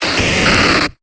Cri de Drascore dans Pokémon Épée et Bouclier.